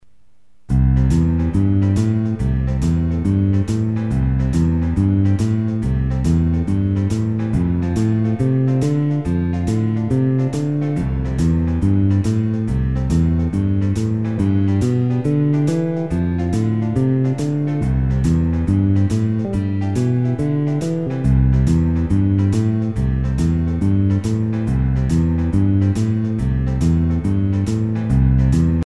Tanssi: Boogie woogie
• Musiikki: Esim. 50's rock'n'roll- ja rythm'n'blues-musiikki
• Nelijakoista
• Selvästi kuuluva walking bass esim. läskibassolla
• Sufflekomppi, kolmimuunteisuus kuuluu selvästi
• Esim. pianolla, virvelillä ja/tai bassolla, joskus haijalla
• "Juurevampi" tunnelma kuin jivessä
• Back beat
• Tyypillisesti breikkejä
boogiewoogie.mp3